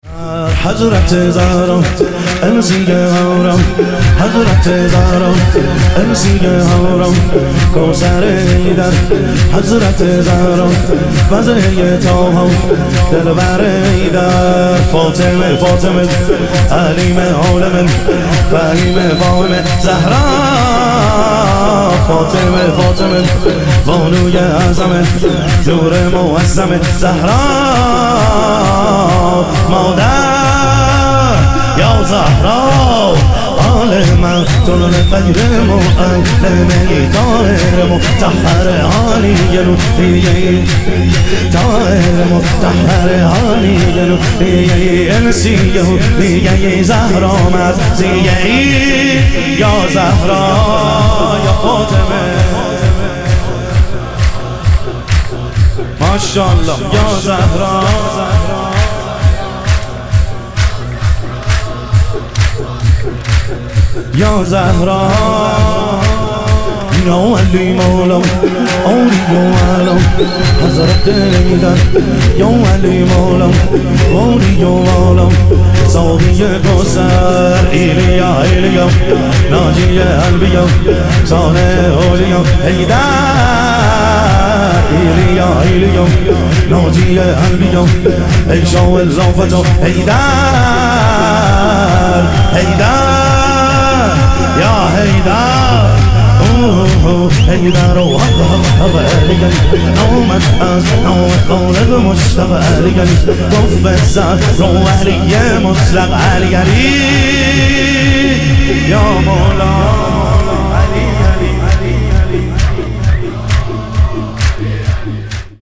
شور
شب ظهور وجود مقدس حضرت زهرا علیها سلام